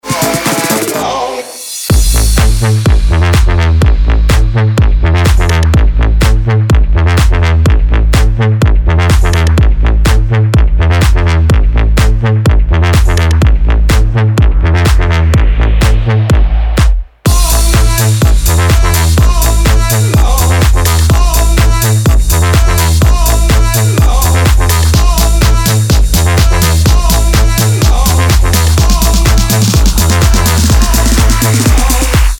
• Качество: 320, Stereo
мужской голос
dance
Electronic
future house
Стиль: Future house